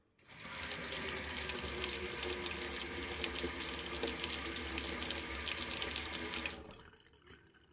灌装水杯 / colocando agua na xicara
描述：此声音是使用Zoom H1录音机录制的，并在大胆上进行编辑。
标签： 填充 水槽 填充 阿瓜 COPO DE enchendo 液体 水龙头 浇注
声道立体声